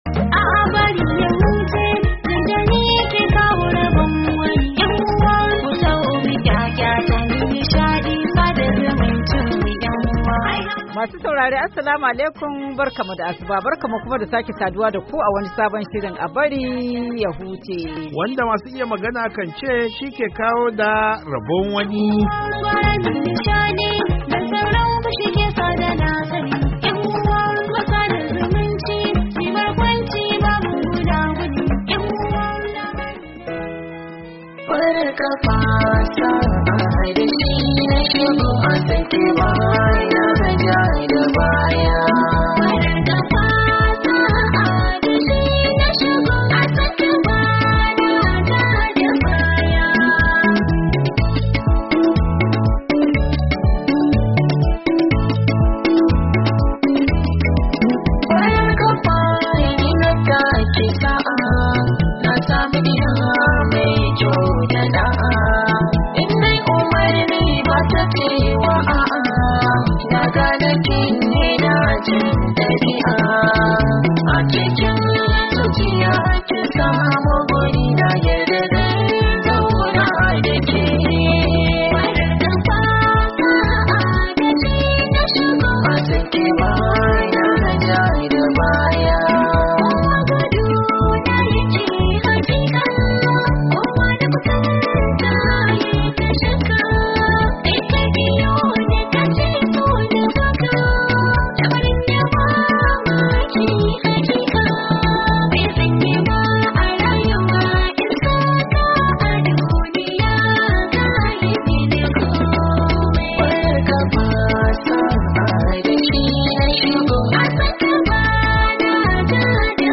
A BARI YA HUCE: Tattaunawa kan zaben da za'a gudanar a Amurka cikin mako mai zuwa Nuwamba, 2, 2024